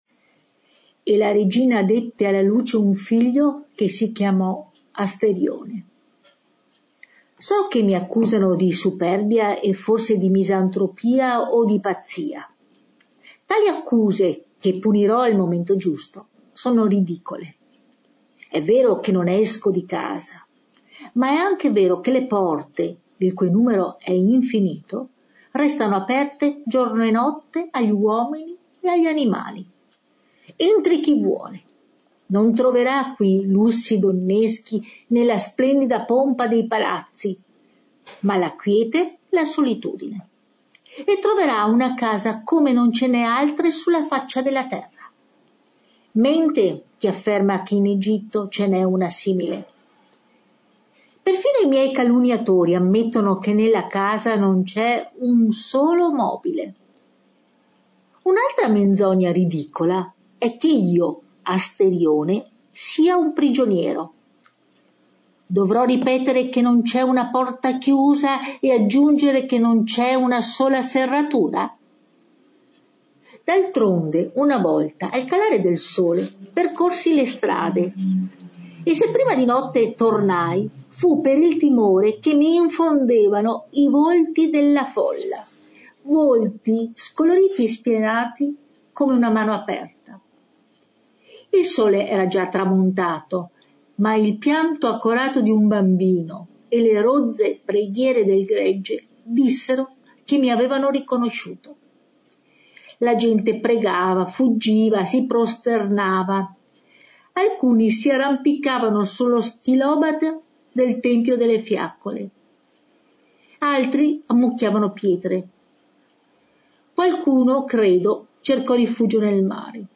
in viva voce